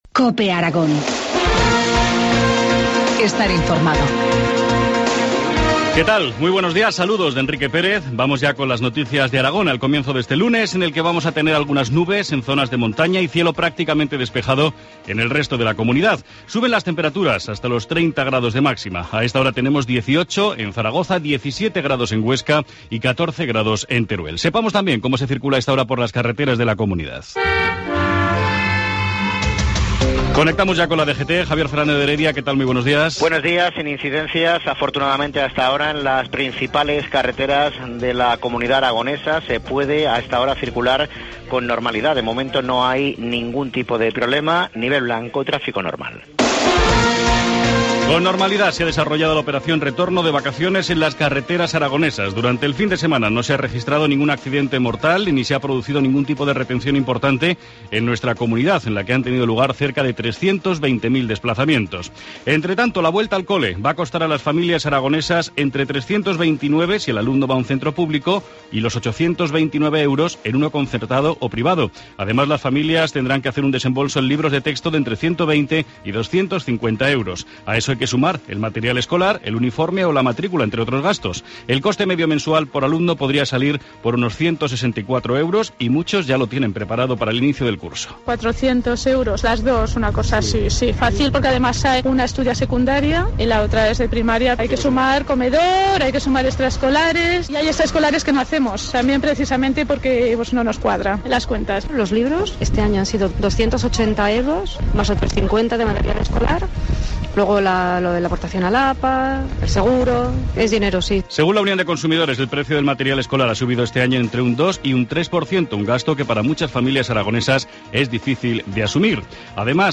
informativo matinal, lunes 2 de septiembre, 7.25 horas